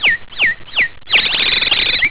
Uccelli 2
uccelli2.wav